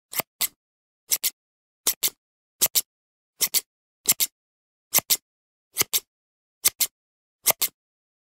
Подборка включает четкие и реалистичные записи, которые подойдут для видео, подкастов или звукового оформления.
Ножницы режут бумагу